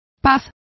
Complete with pronunciation of the translation of tranquillity.